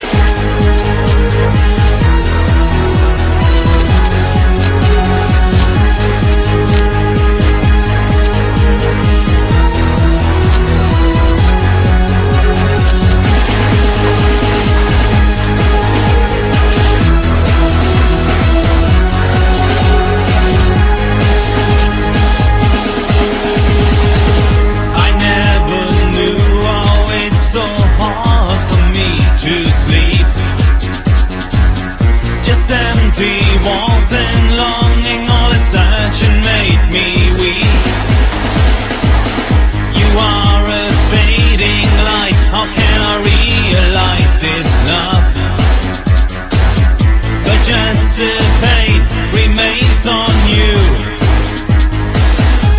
contains Electro,Ethno,Industrial,Dark,Experimental.. etc.
you will hear some cut&paste excerpts..